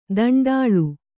♪ daṇḍāḷu